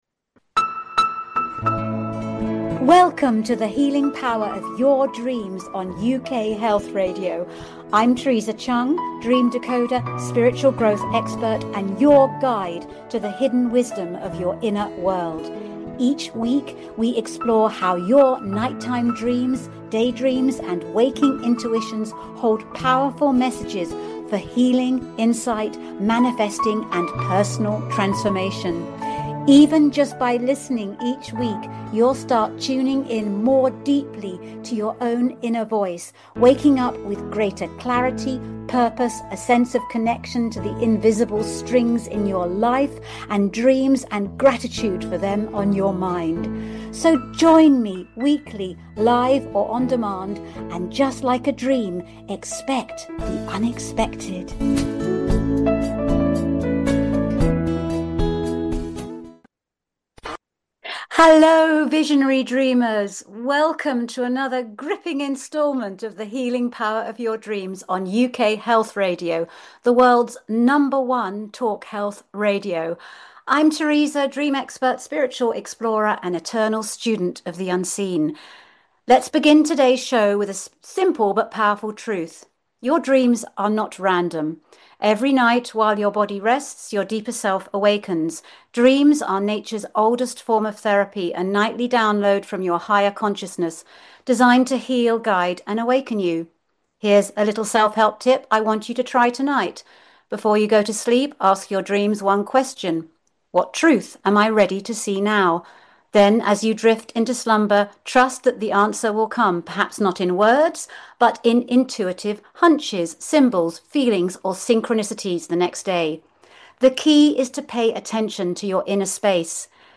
The Healing Power of Dreams explores the transformative power of nocturnal dreams and day dreams for your mental, emotional, physical, and spiritual wellbeing. Each live episode dives deep with leading scientists, psychologists, authors, and consciousness researchers, plus the occasional celebrity guest sharing their own dream stories.